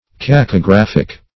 Search Result for " cacographic" : The Collaborative International Dictionary of English v.0.48: Cacographic \Cac`o*graph`ic\, a. Pertaining to, or characterized by, cacography; badly written or spelled.